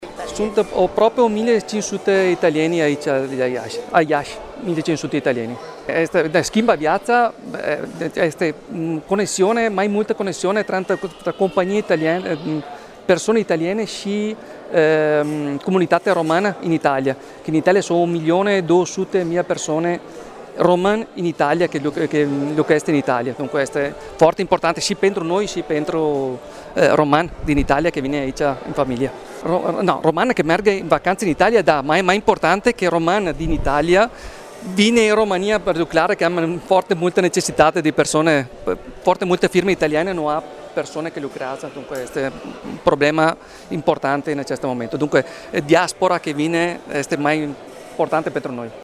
Evenimentul de lansare a rutei Iași-Cuneo, dedicat pasagerilor, a avut loc într-un cadru festiv, în sala de Plecări a terminalului T3, unde s-au ținut discursuri, invitații au tăiat tortul tematic și au tăiat panglica pentru a marca, în mod simbolic, inaugurarea noii rute.
Consulul onorific al Italiei în Iași,Enrico Novella